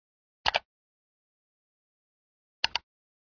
click.wav